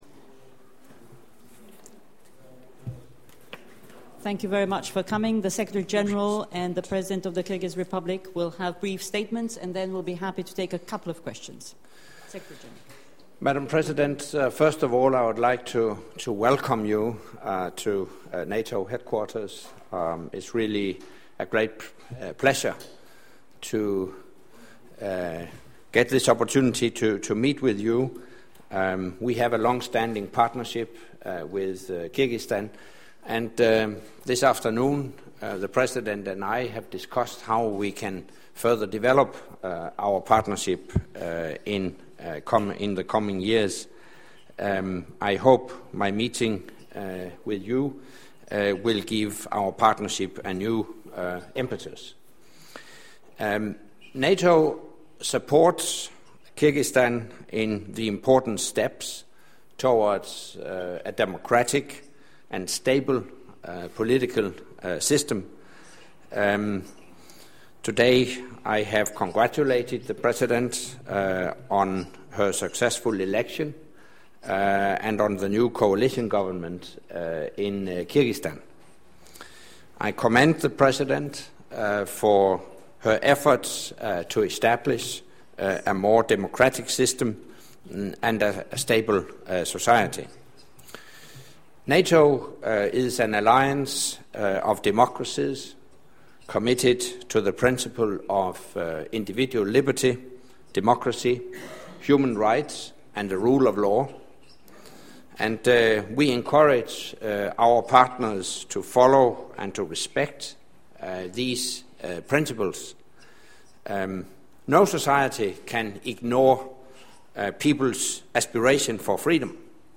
Audio Point de presse avec le secrétaire général de l'OTAN et la la présidente de la République kirghize, opens new window